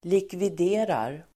Uttal: [likvid'e:rar]